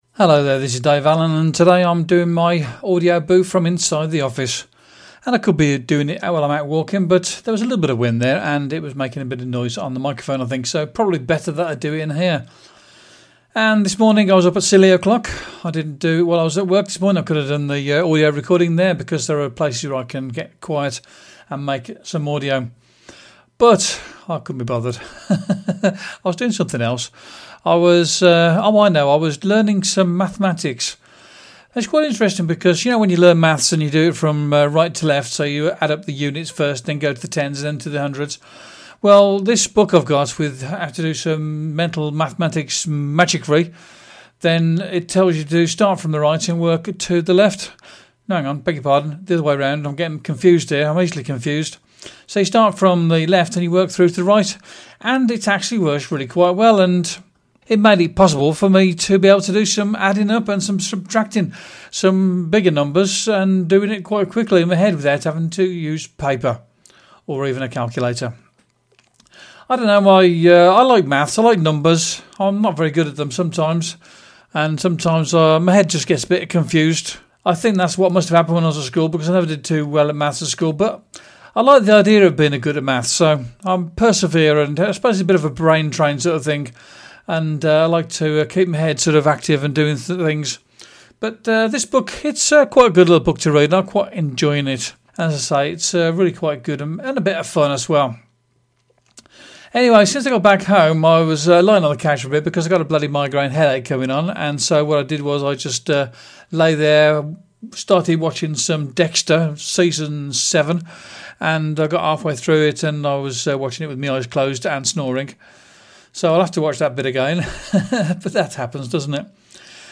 Because of the wind I didn't do the recording of the Audioboo was outside walking the dog as per usual and I have recorded in the studio or office.